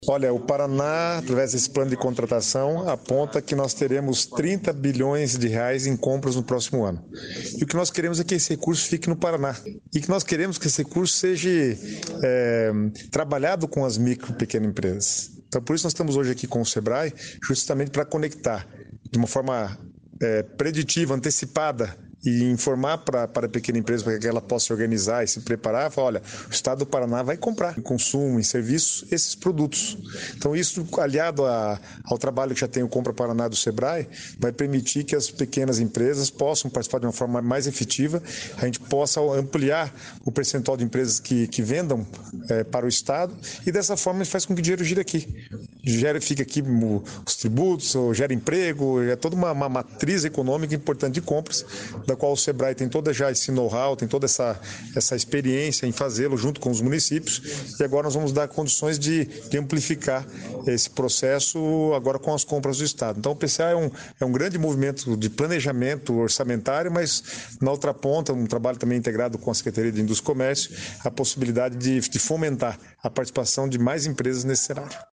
Sonora do secretário do Planejamento, Guto Silva, sobre plano de contratações anual